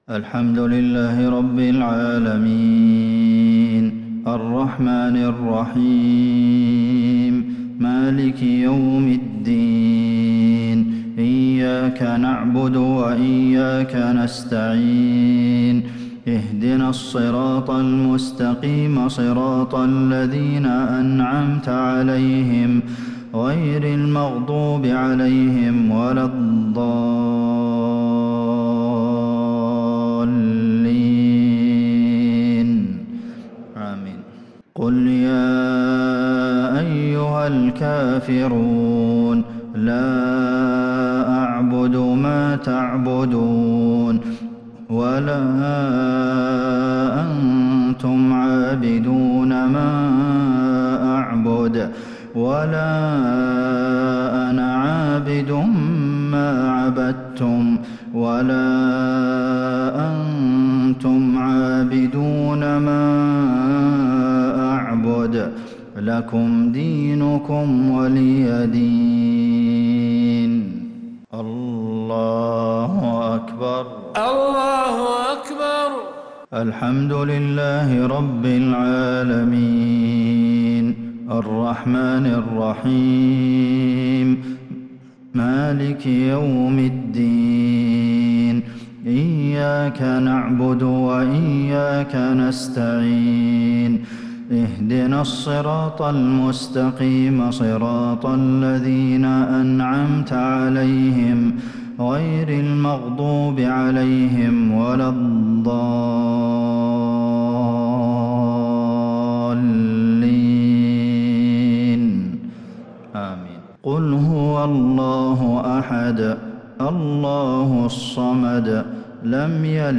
صلاة المغرب للشيخ عبدالمحسن القاسم 13 شوال 1442 هـ
تِلَاوَات الْحَرَمَيْن .